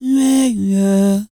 E-CROON 3019.wav